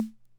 Conn Min-O-Matic Rhythm Sample Pack_Snare.wav